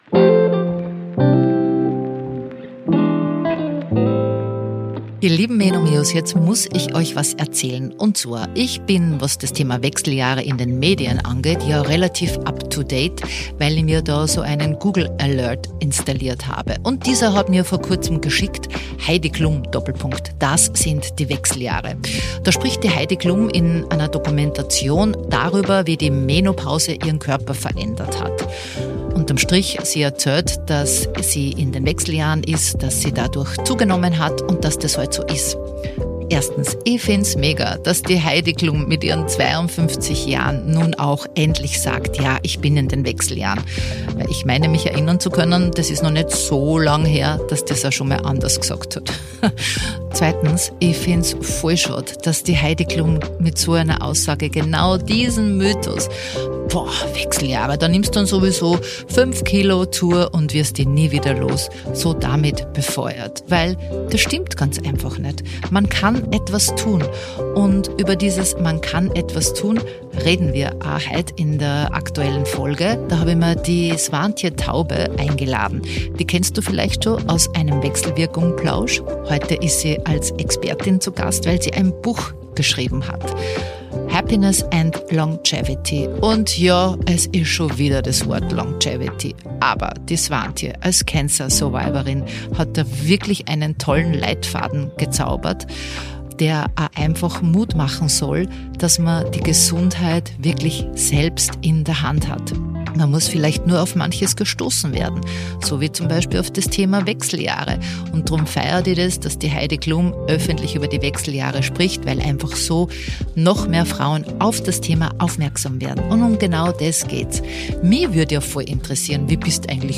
Ein Gespräch über die „Superpower Happiness“, die unterschätzte Kraft der Muskeln und warum wir zum Großteil unsere Gesundheit aktiv selbst steuern können. Ein offenes Gespräch auf Augenhöhe – für alle Frauen, die ihre Gesundheit aktiv gestalten wollen, statt nur abzuwarten.